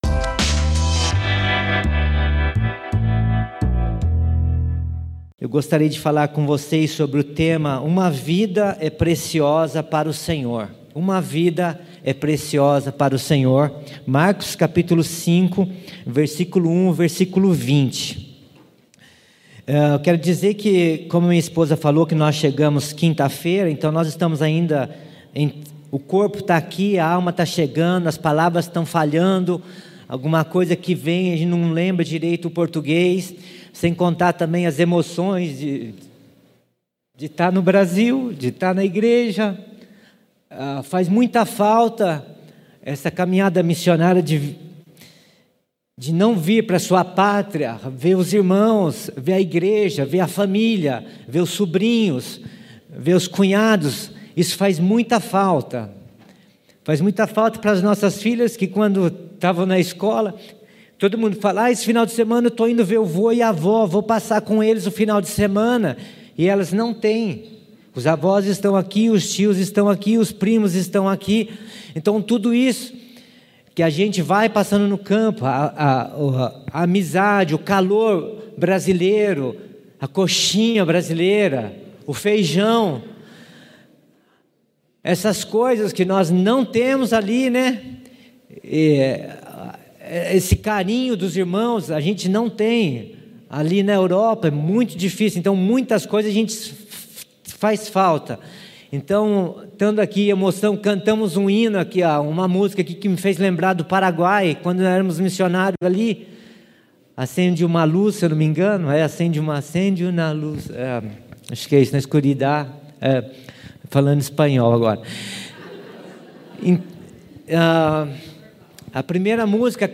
Mensagem ministrada pelo missionário